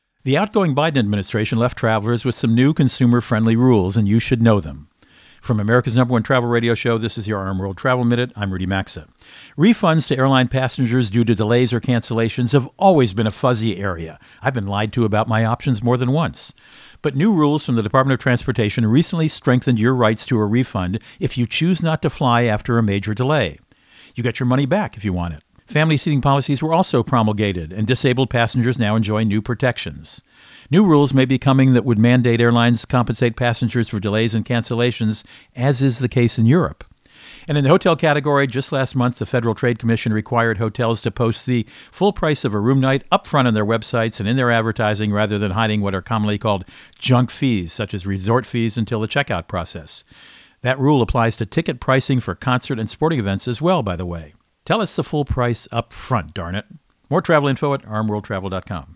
Co-Host Rudy Maxa | Be Aware of these New Consumer Rules for Travelers